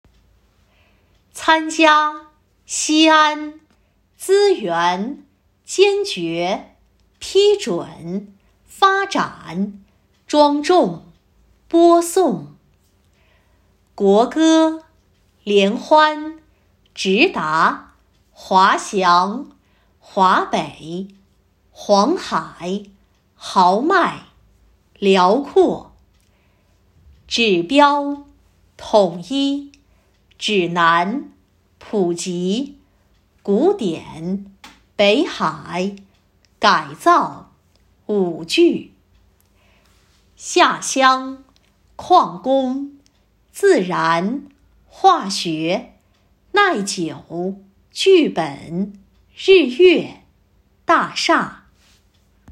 领读课件
4月第一周-领读课件.m4a